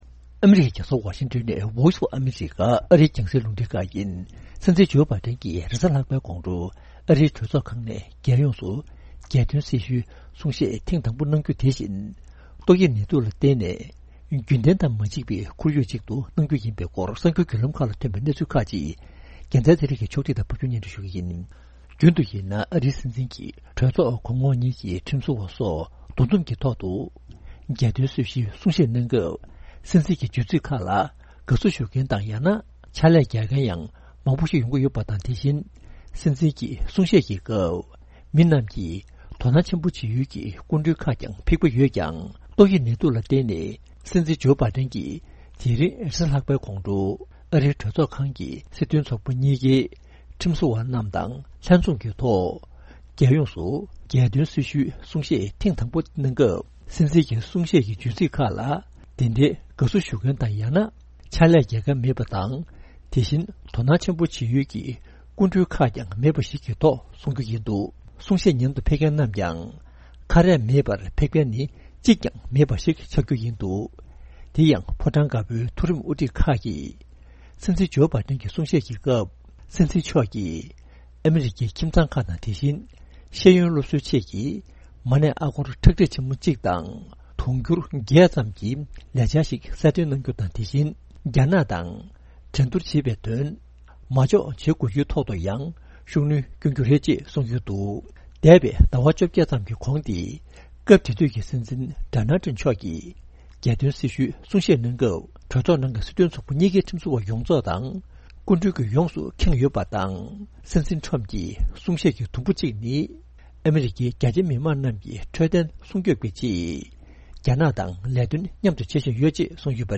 སྲིད་འཛིན་བྷ་ཌན་གྱིས་གྲོས་ཚོགས་གོང་འོག་ལ་གསུང་བཤད་གནང་བ།